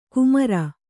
♪ kumara